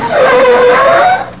CARSTOP.mp3